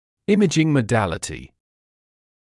[‘ɪmɪʤɪŋ mə’dælətɪ][‘имиджин мэ’дэлэти]способ получения диагностических изображений